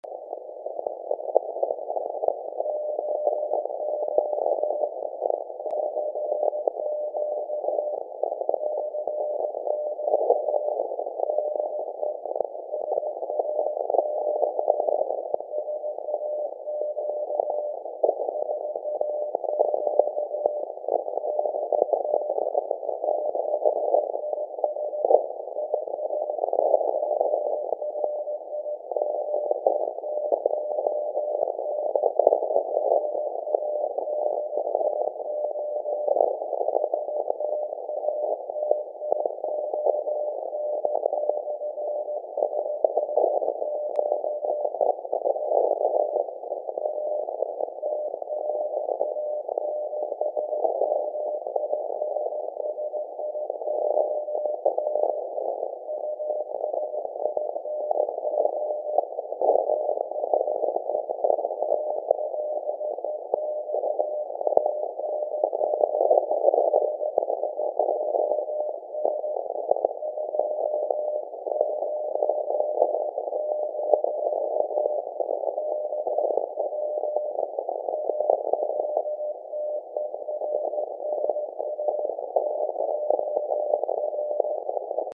The station was dedicated 100 years ago on 1 December, 1924. For that reason, the 17.2 kHz alternator was scheduled for a commemorative transmission at 1000 UTC (5 AM Eastern Standard in North America) on Sunday, December 1.